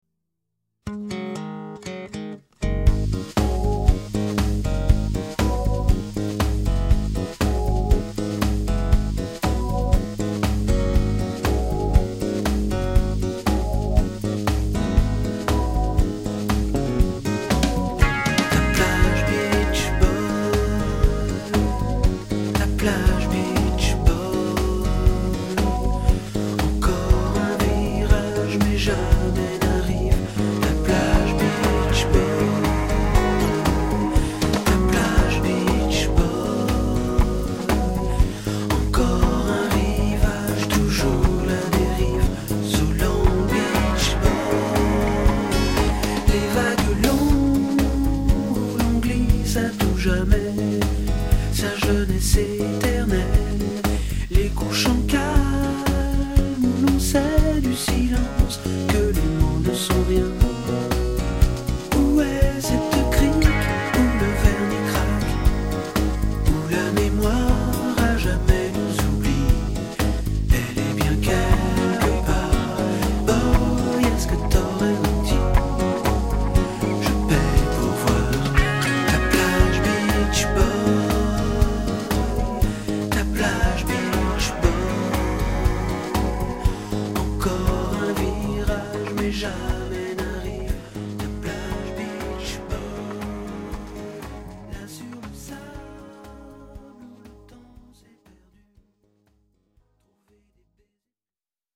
tonalité SI majeur